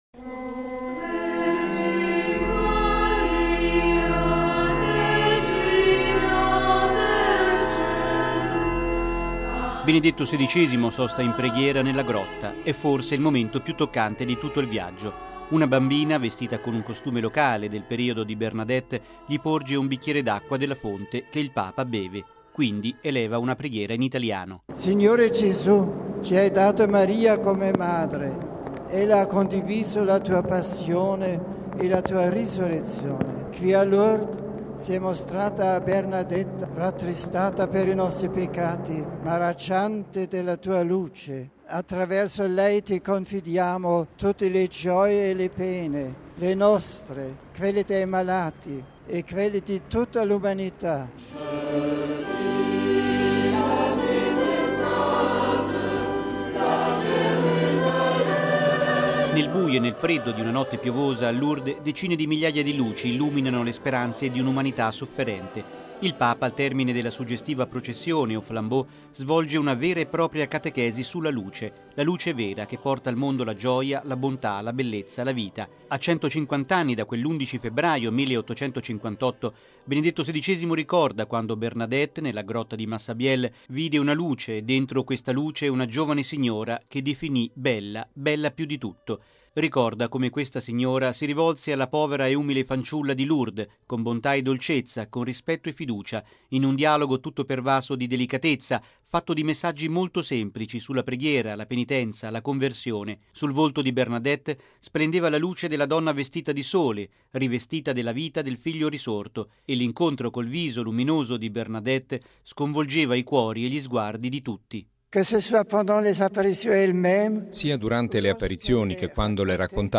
In serata, al termine della processione aux flambeaux, il Papa ha tenuto l’omelia dalla terrazza inferiore della Basilica di Notre Dame di Lourdes.
(Canto)